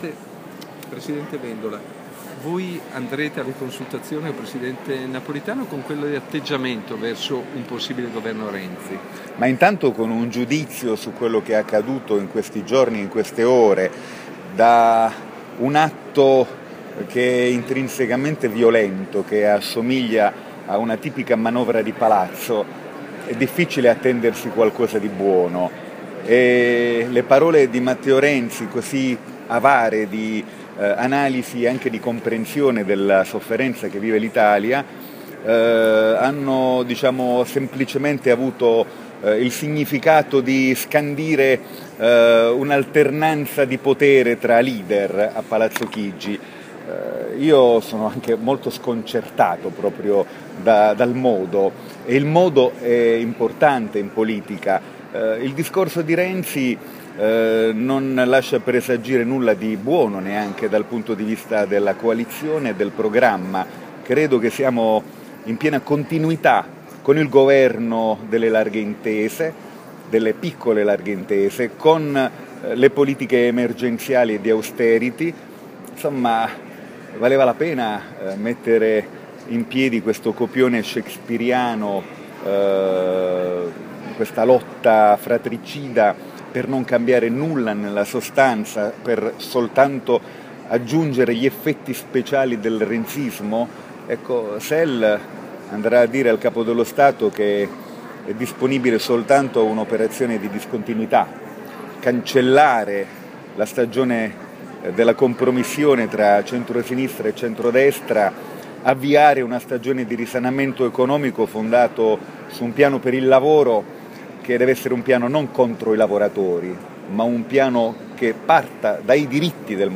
Audio dichiarazione Nichi Vendola